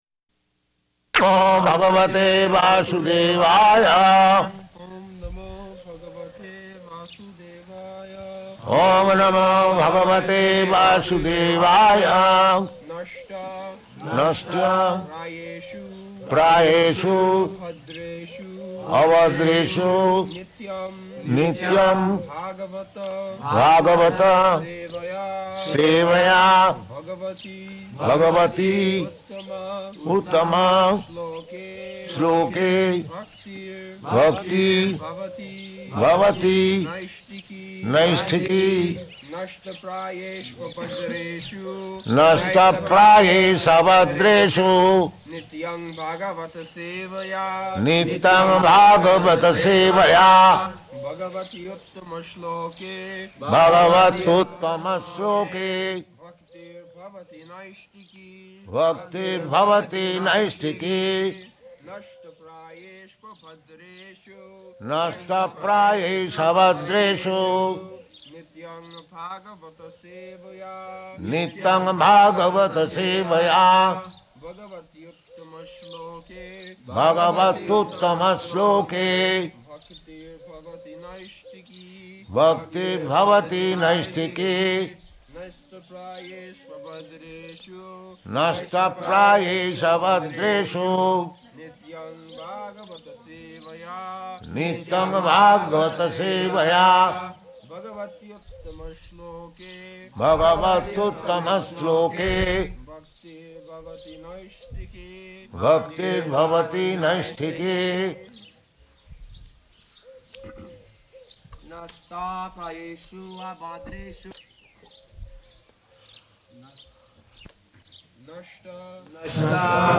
October 29th 1972 Location: Vṛndāvana Audio file
[Prabhupāda and devotees repeat]